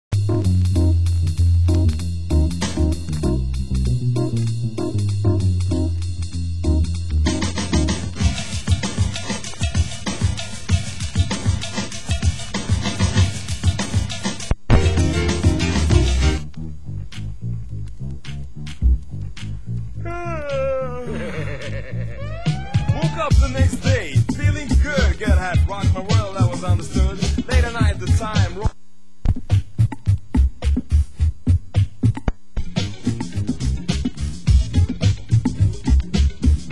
Genre Euro House